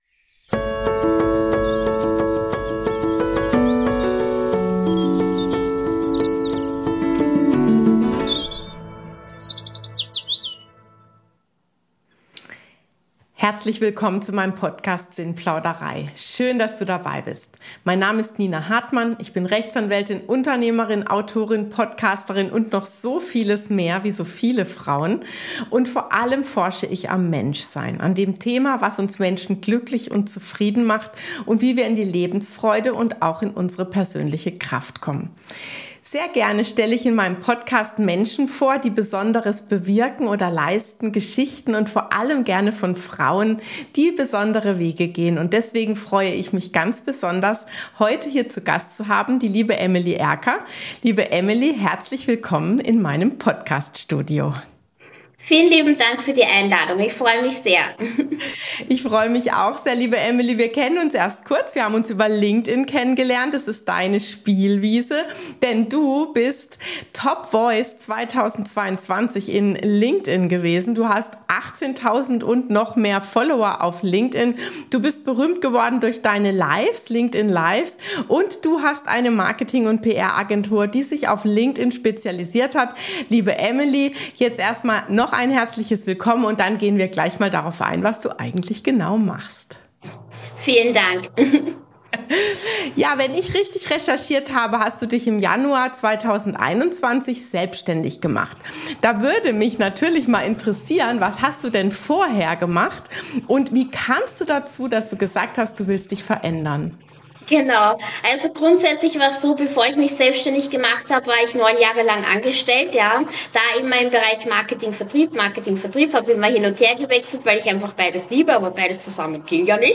Ein Gespräch über Berufung, Branding und die Kraft weiblicher Wege.